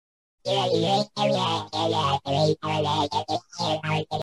Alien Cat says yaer aegh sound effects free download
Mp3 Sound Effect